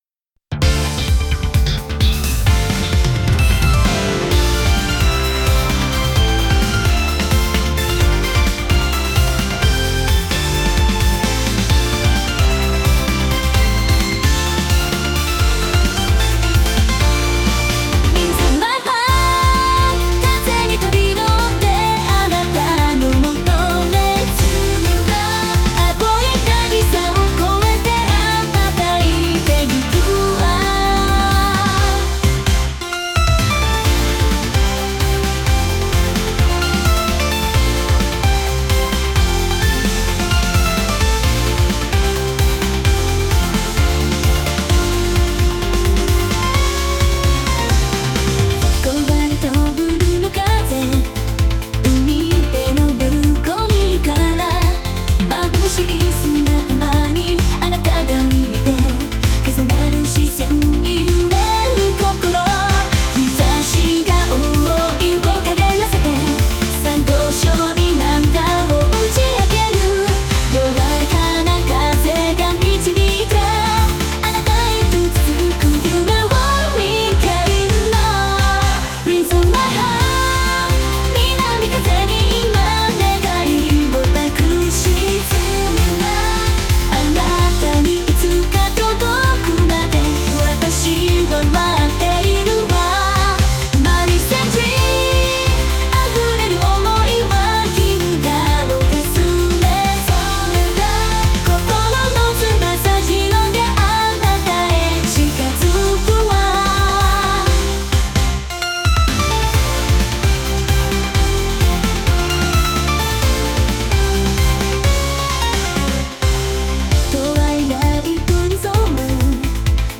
AI生成 懐メロ音楽集